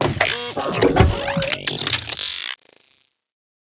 tug.ogg